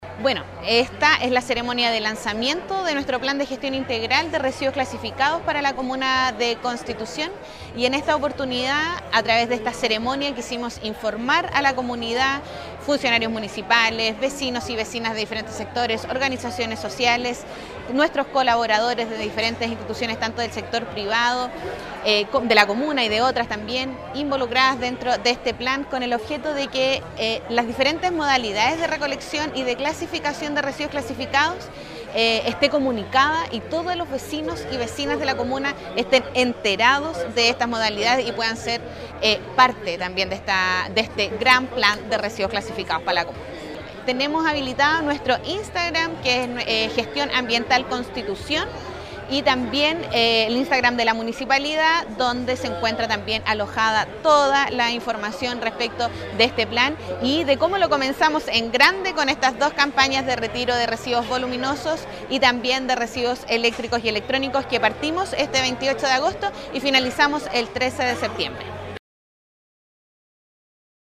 Este martes, en el Hall del Teatro Municipal, se llevó a cabo el lanzamiento del Plan de Gestión de Residuos Clasificados, una iniciativa impulsada por el Departamento de Medio Ambiente de la Dirección de Aseo, Ornato y Medio Ambiente.